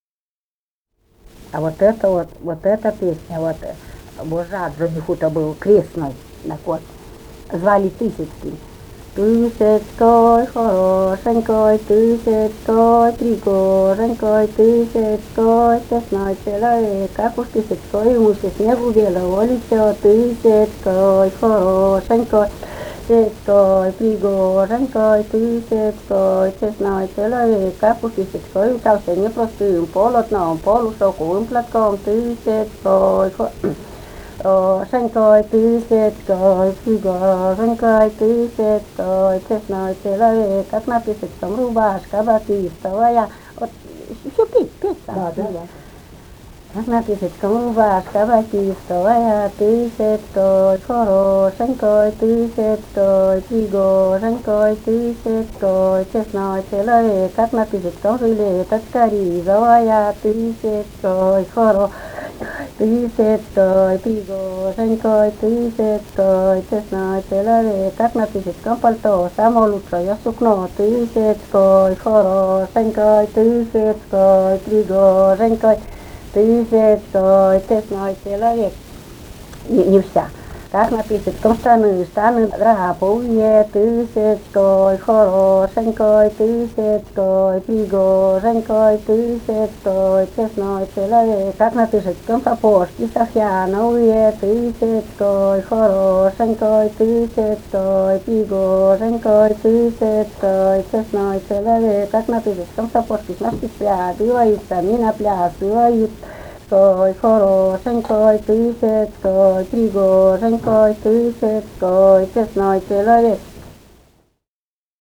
Файл:FCMC 016-06 Тысяцкой хорошенькой (свадебная) И1129-07 Малая.mp3 — Фолк депозитарий